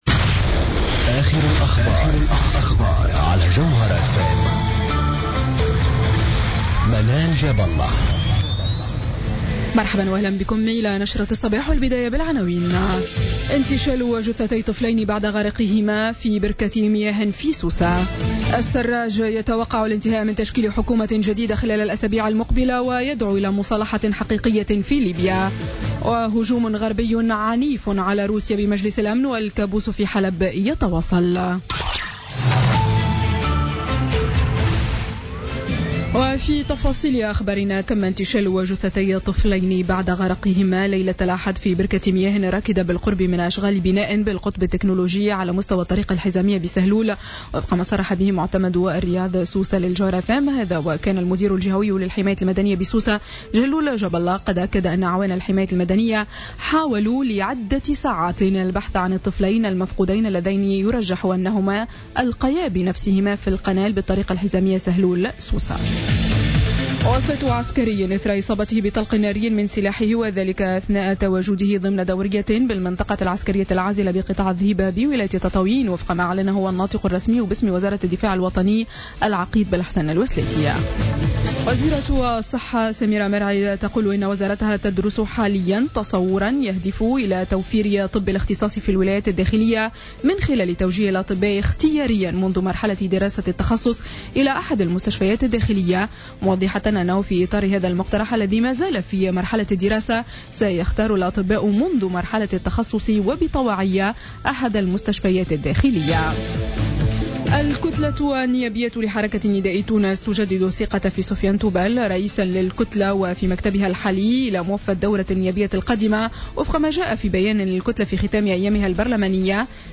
نشرة أخبار السابعة صباحا ليوم الاثنين 26 سبتمبر 2016